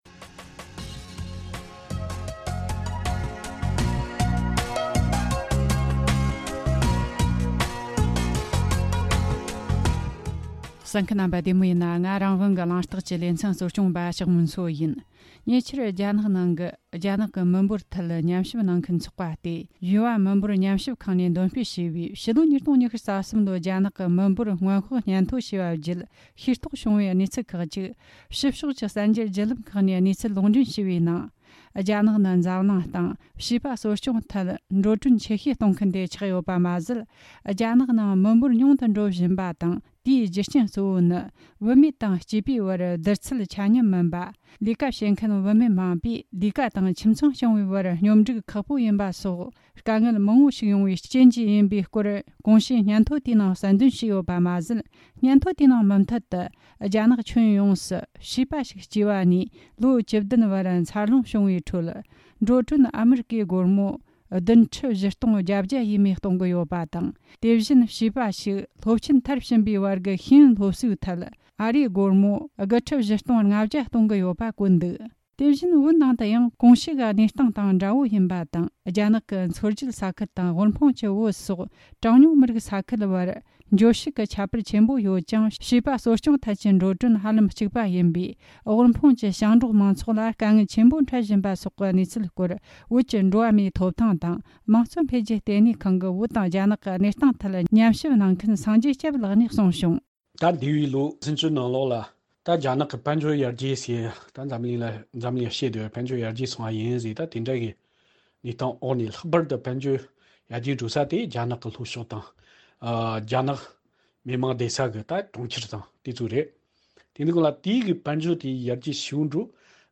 འབྲེལ་ཡོད་མི་སྣར་བཅར་འདྲི་དང་གནས་ཚུལ་ཕྱོགས་བསྡུས་བྱས་བར་གསན་རོགས་གནོངས།།